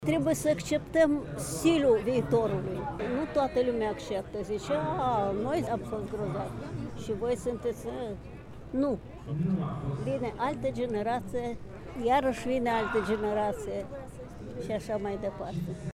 În același timp, Marina Voica este de părere că fiecare gen muzical trebuie susținut: